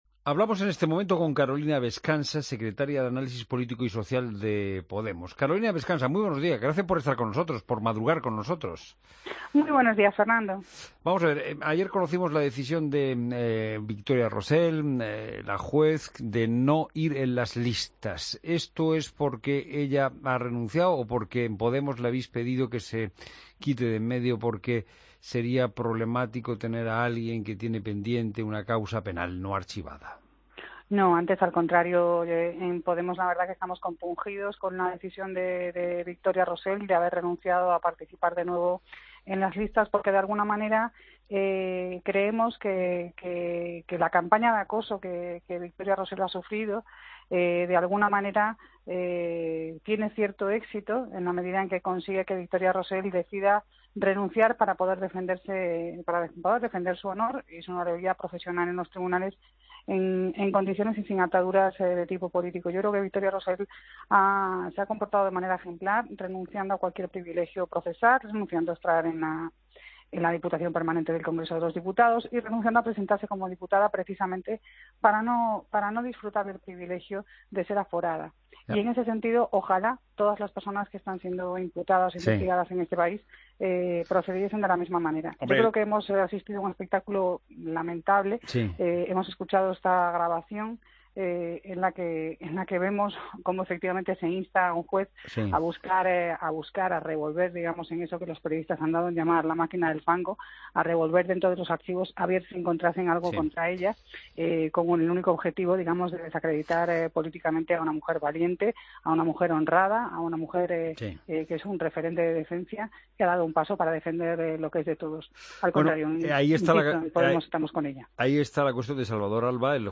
Escucha la entrevista a Carolina Bescansa, secretaria de Análisis Político y Social de Podemos, en La Mañana de Fin de Semana